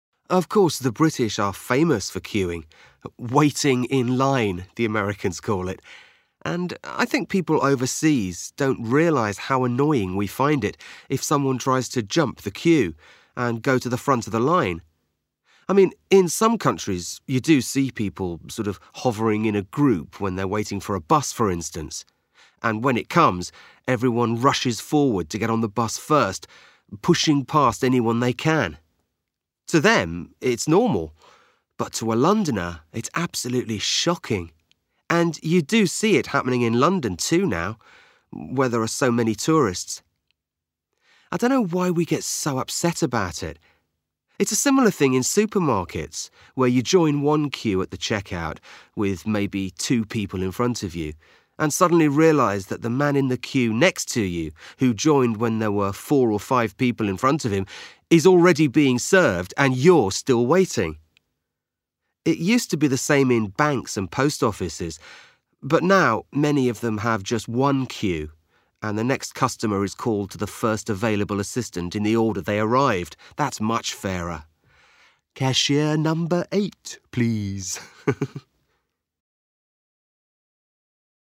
This activity provides short listening practice based around a monologue regarding queuing etiquette in the UK. The monologue provides observations of queuing.